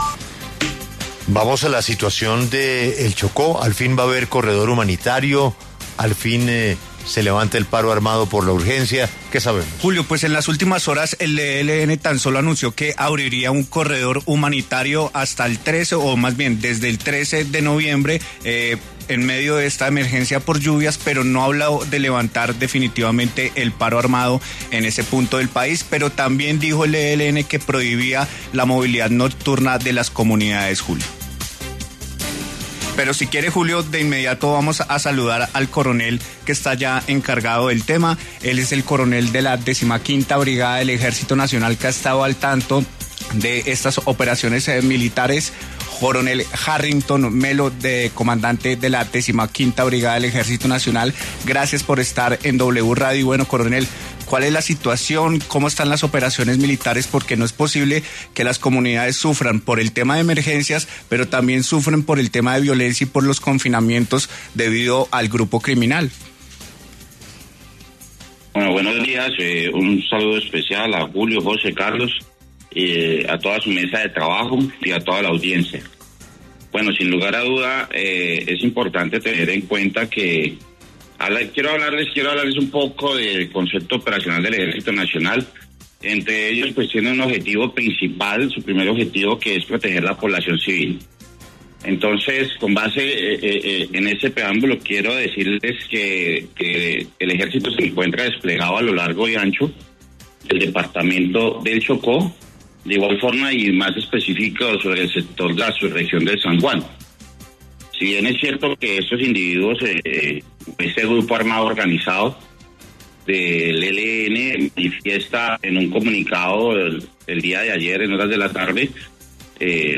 En diálogo con La W